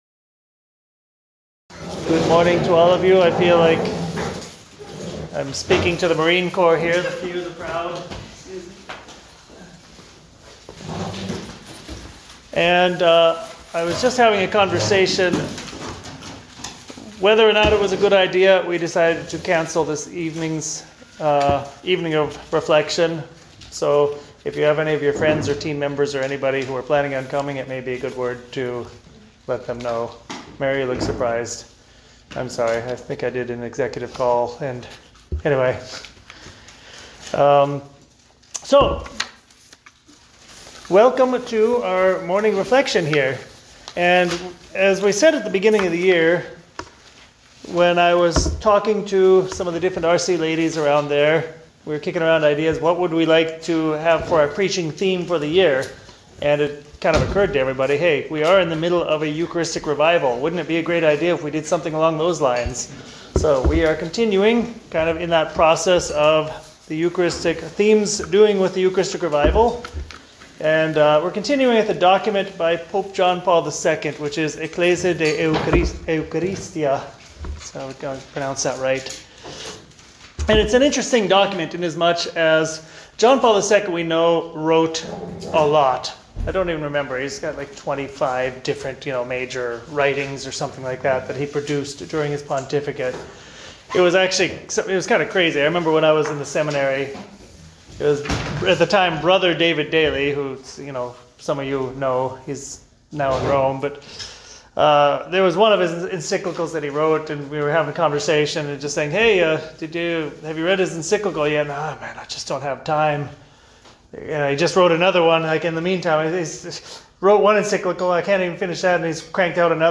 Morning Reflection Talk and Meditation - RC NY Tri-State
FEn_Talk_MOR-The-Eucharist-and-Ecclesial-Unity.mp3